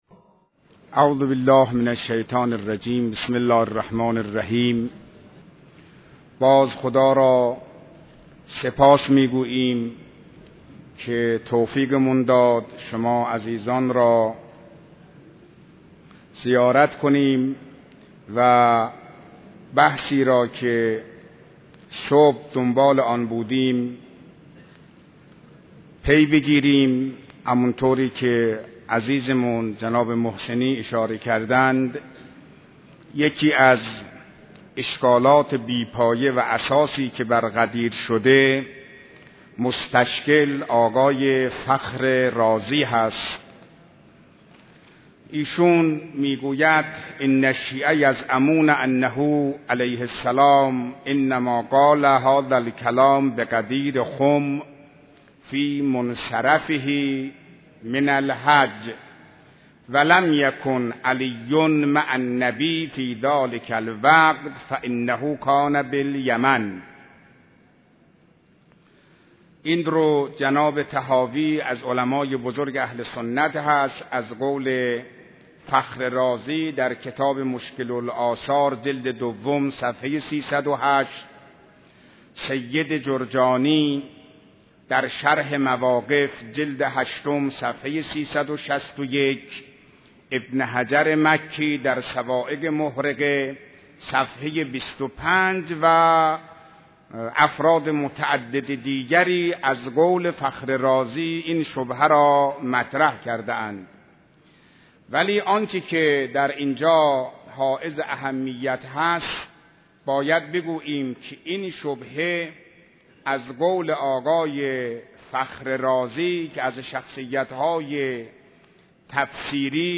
بیانات ارزشمند آیت الله حسینی قزوینی با موضوع «غدير از منظر اهل سنت» به مناسبت عید غدیر خم / مدت زمان : 22 دقیقه